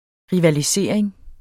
Udtale [ ʁivaliˈseˀeŋ ]